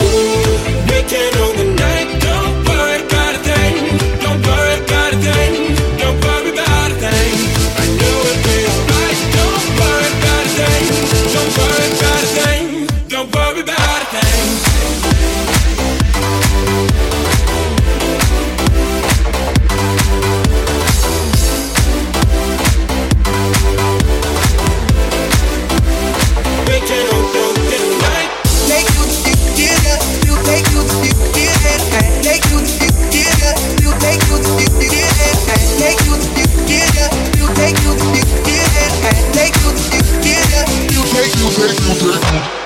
Genere: pop, deep, dance, house, club, successi, remix